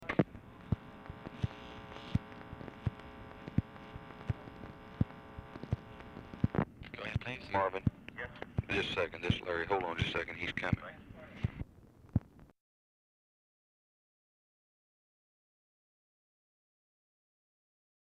Telephone conversation
Format Dictation belt